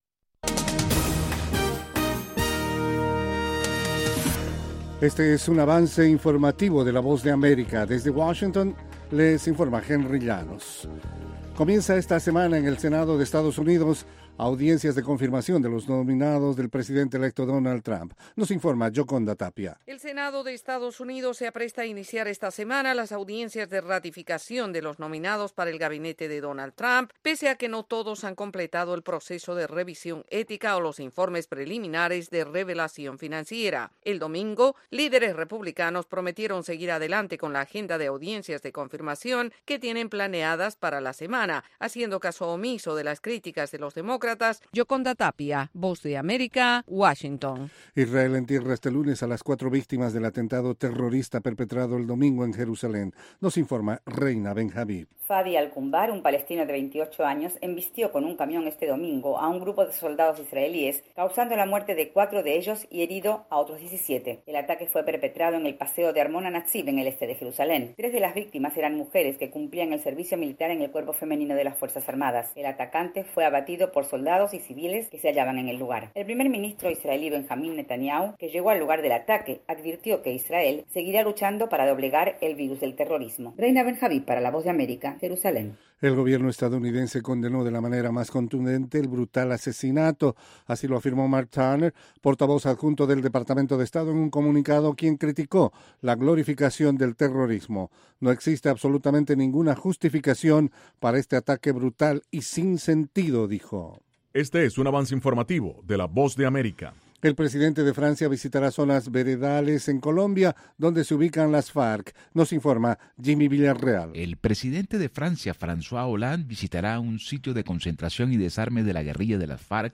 Capsula informativa de 5 minutos con el acontecer noticioso de Estados Unidos y el mundo.